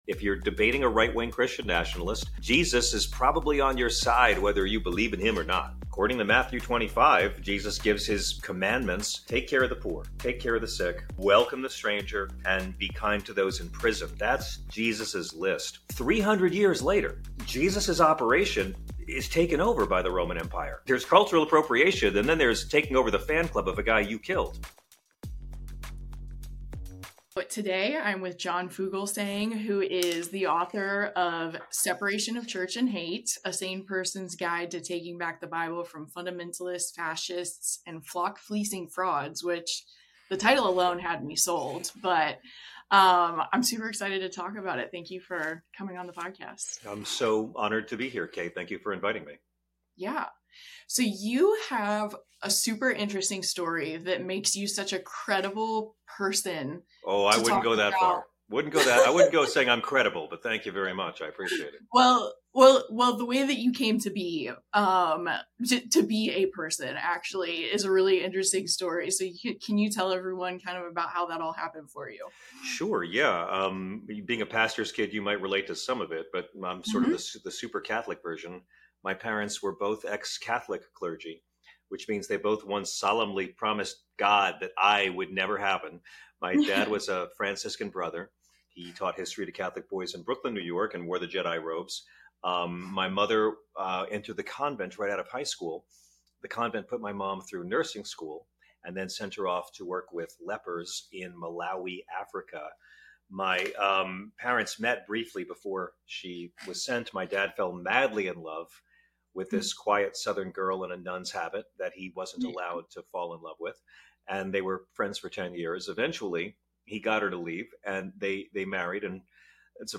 This week, I got to talk with John Fugelsang about his new book Separation of Church and Hate! He shares why he's passionate about taking back the religion of his parents from the way it has been used as a cloak for hate, how it's near impossible to find a quality in Donald Trump that aligns with Jesus' teachings, and ways to lead Christian Nationalists to arguing with God/scripture instead of yourself.